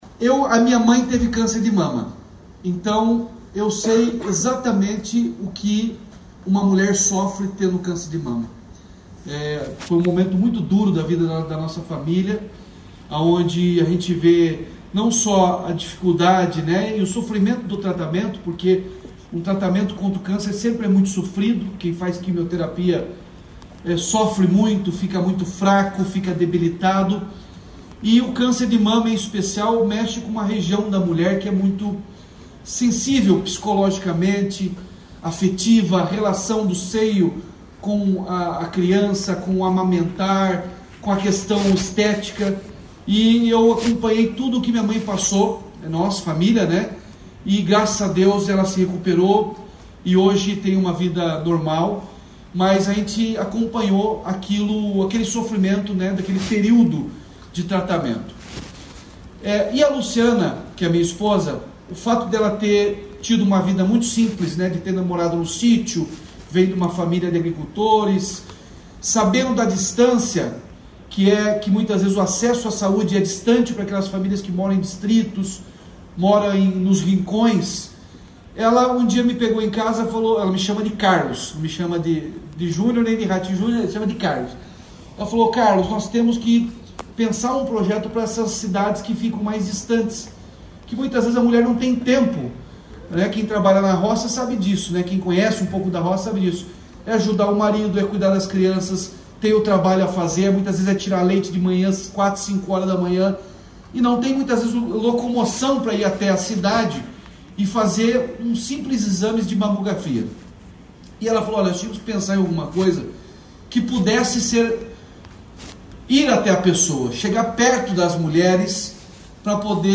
Sonora do governador Ratinho Junior sobre o lançamento do campanha Paraná Rosa de 2025